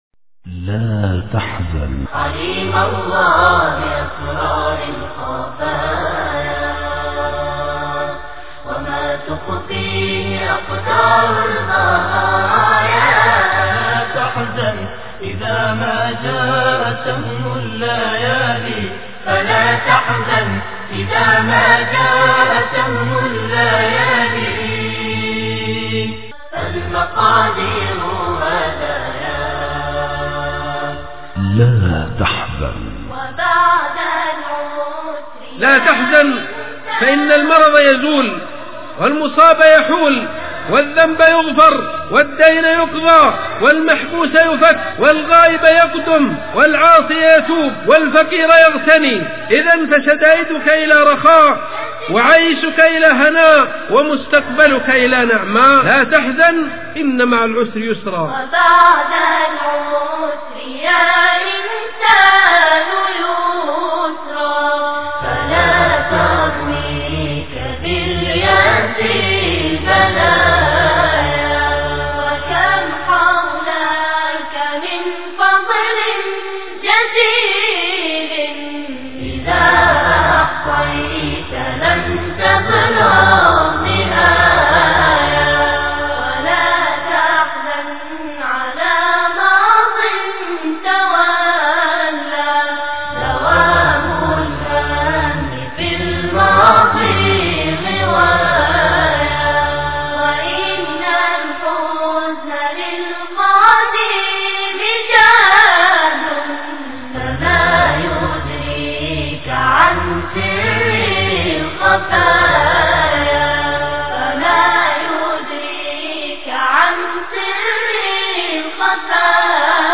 (انشودة لا تحزن بصوت الاطفال+رقائق الشيخ عائض القرنى) غاية الروعة والجمال